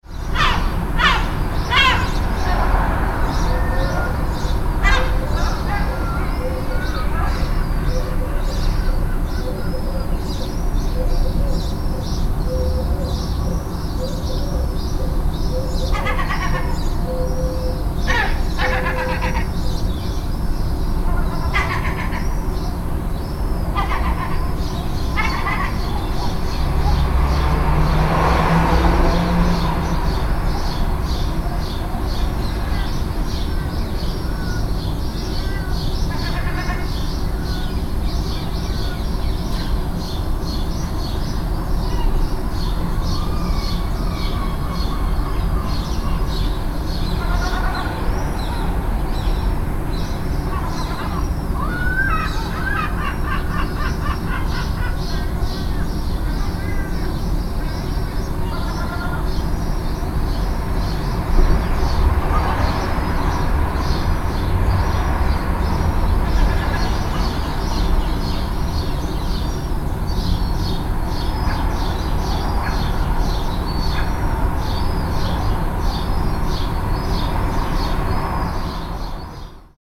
Seagulls-and-city-noise-street-ambience-sound-effect.mp3